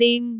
speech
syllable
pronunciation
dim1.wav